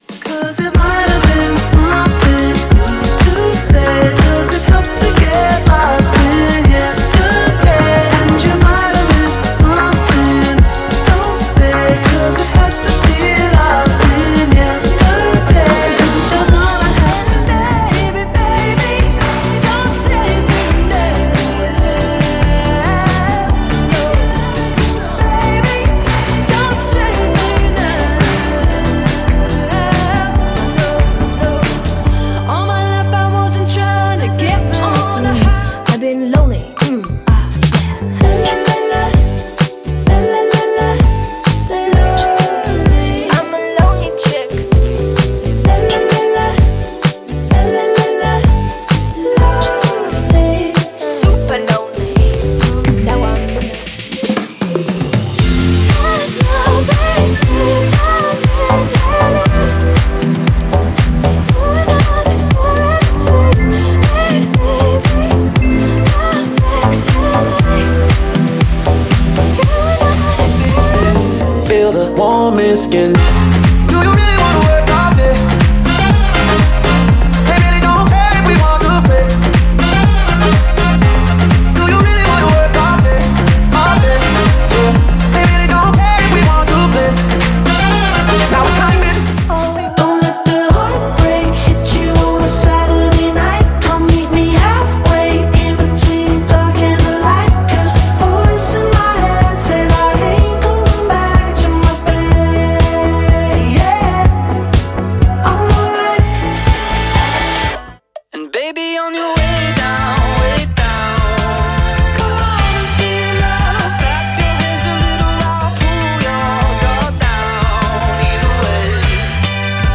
Breezy Indie Pop